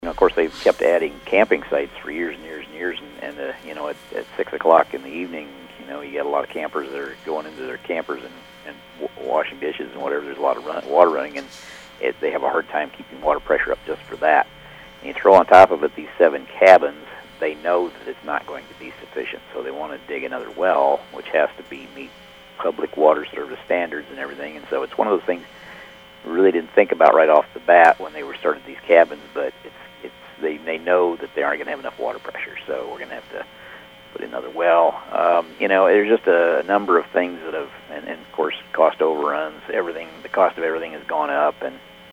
Audubon County Board of Supervisor Chairman Doug Sorensen says the additional cost is for the rock, sidewalk, and well.